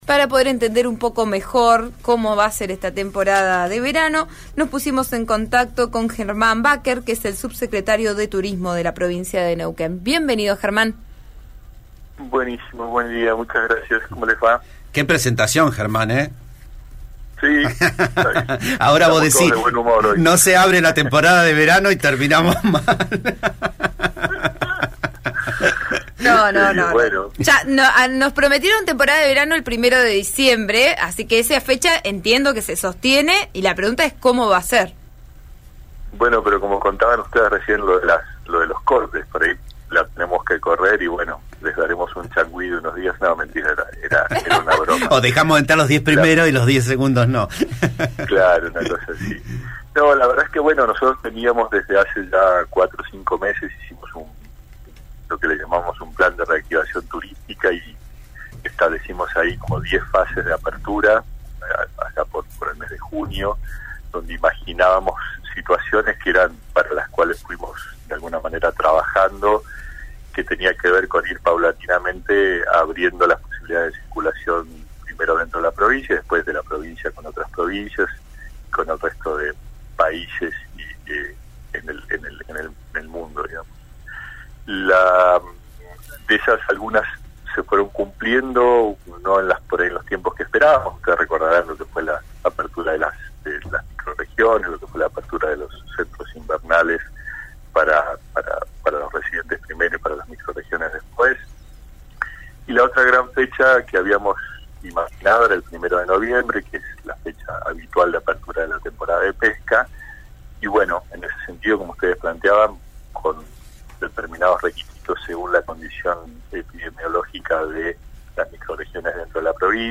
En diálogo con Vos a Diario, el programa de RN Radio
German-Bakker-subsecretario-de-Turismo-de-Neuquen-online-audio-converter.com_.mp3